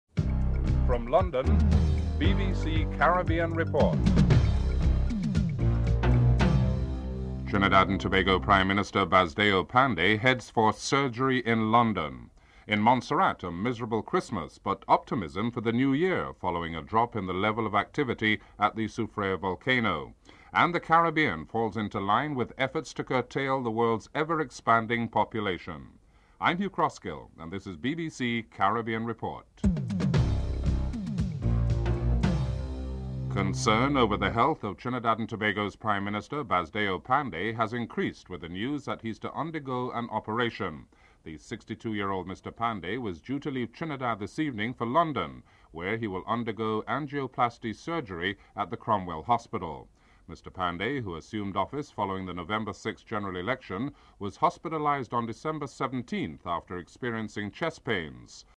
Trinidad and Tobago's Prime Minister Basdeo Panday heads to London to undergo heart surgery. Mr. Panday spoke about his upcoming surgery and his hope to be back in time for the budget presentation.
Governor Frank Savage announced the good news.